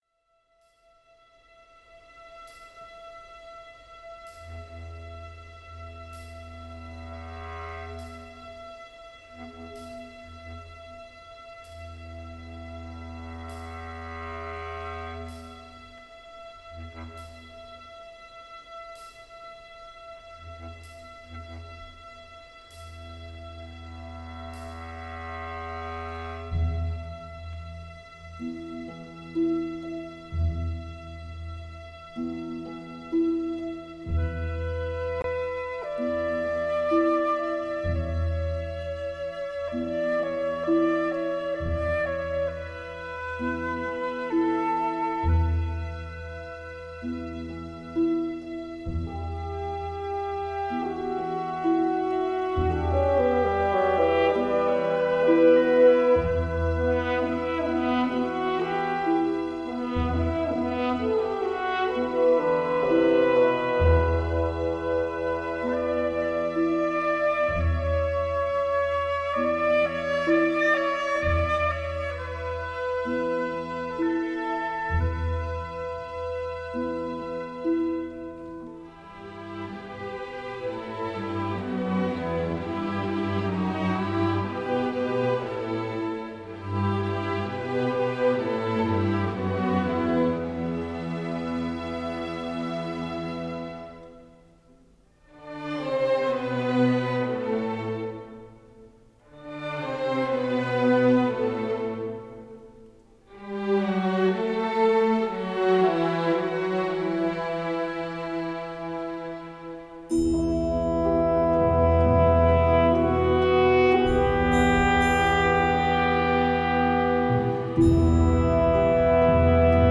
Genre: Balkan Folk, World Music